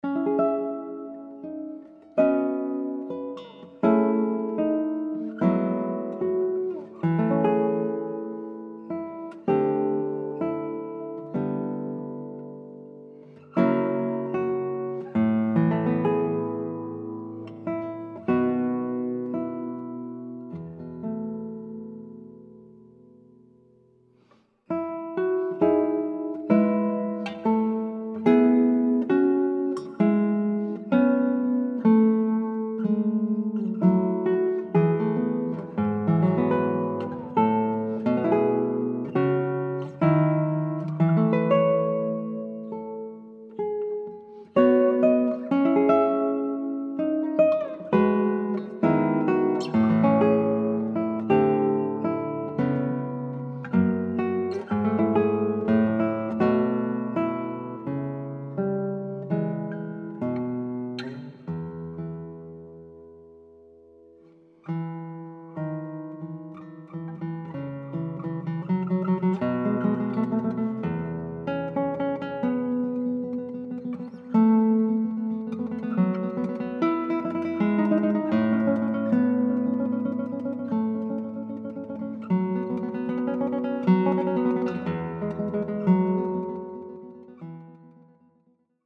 Genre: Classical.